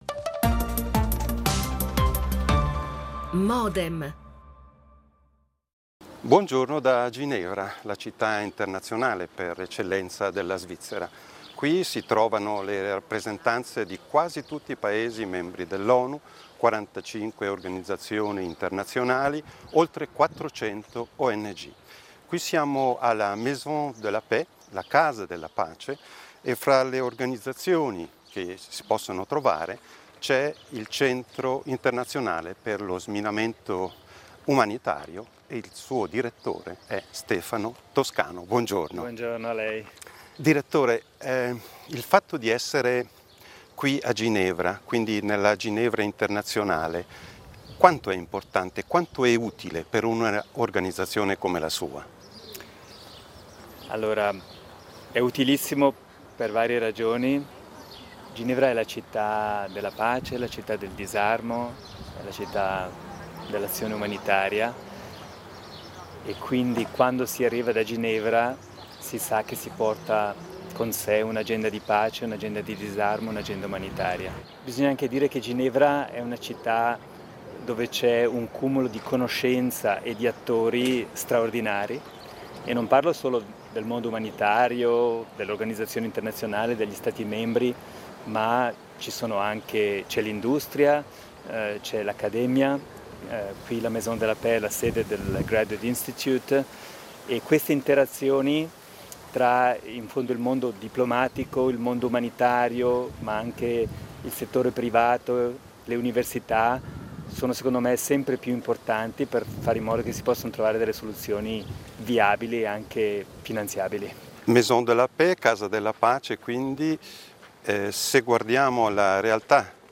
Alla testa del Centro da 10 anni, l’ambasciatore ticinese parla del suo lavoro, delle sue motivazioni, delle fatiche e delle opportunità che la diplomazia presenta in favore di un mondo multilaterale.